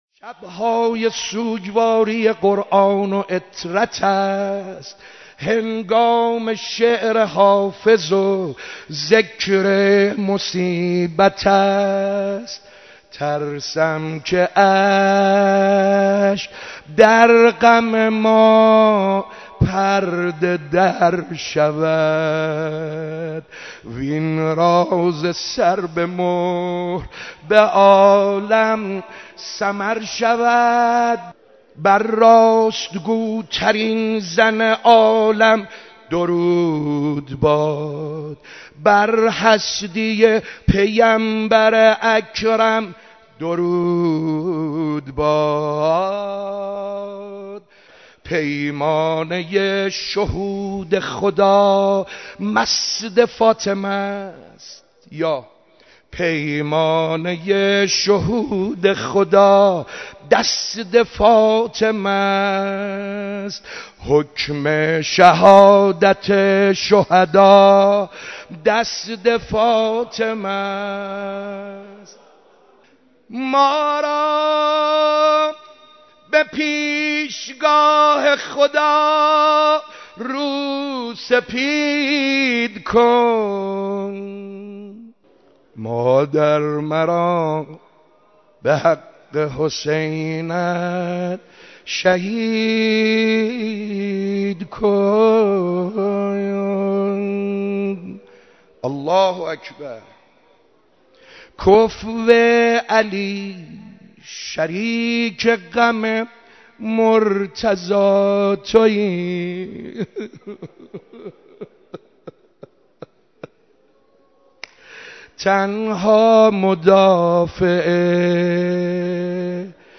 آخرین شب مراسم عزاداری حضرت فاطمه‌زهرا سلام‌الله‌علیها
مداحی جناب آقای سعید حدادیان